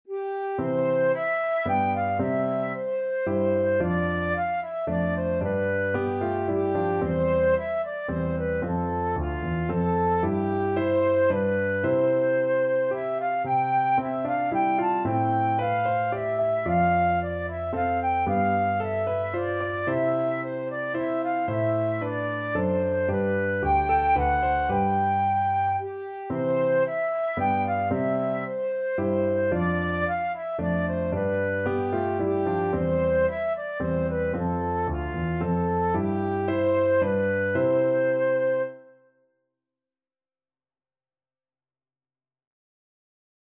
Flute
Traditional Music of unknown author.
C major (Sounding Pitch) (View more C major Music for Flute )
3/4 (View more 3/4 Music)
Moderately Fast ( = c. 112)